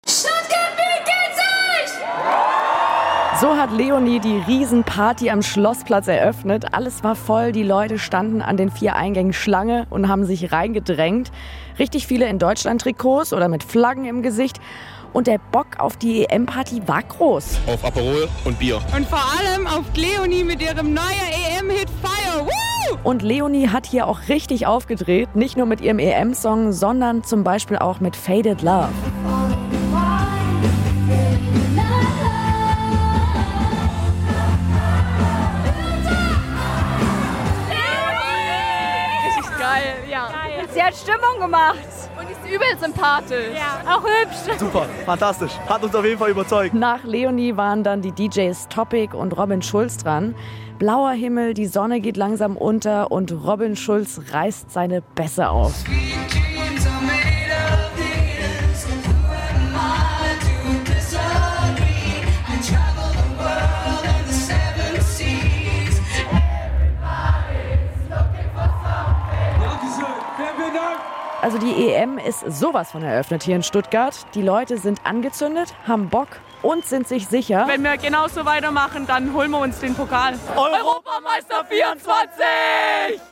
Am Abend haben wir mit euch in Landau in der Pfalz und Stuttgart richtig fett gefeiert. Bei den SWR3-EM-Eröffnungsparties. Allein in Stuttgart waren 20.000 Menschen.